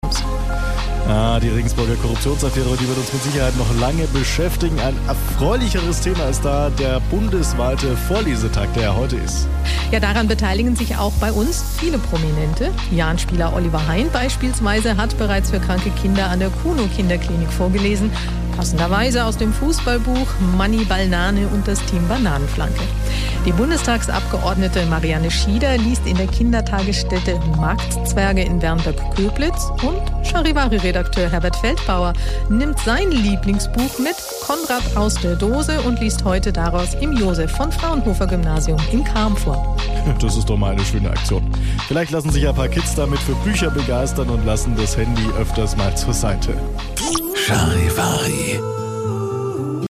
Vorlesetag am Fraunhofer-Gymnasium